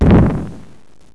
stone_land.wav